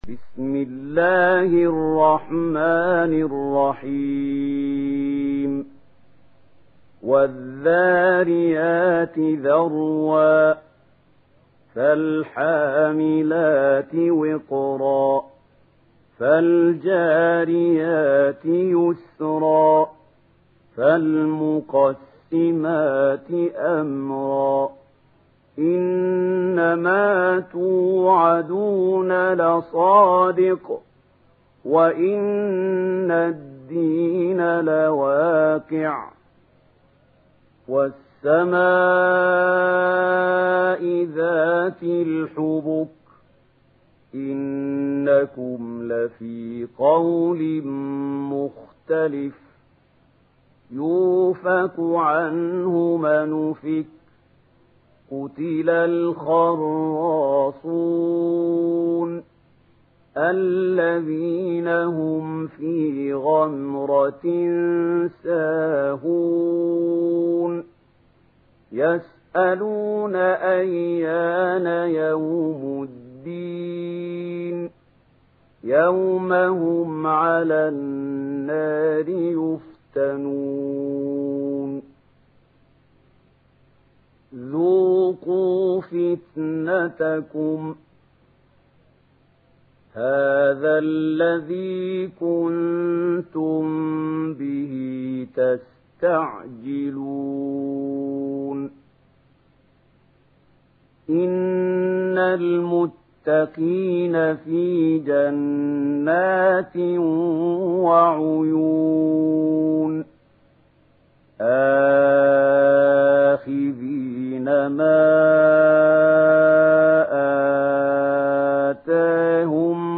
Sourate Ad Dariyat mp3 Télécharger Mahmoud Khalil Al Hussary (Riwayat Warch)